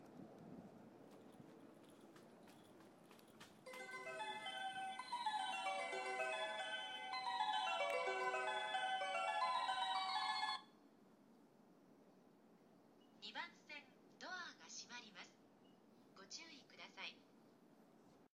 スピーカーは小ボスで音質がたいへんいいです。
発車メロディー余韻切りです。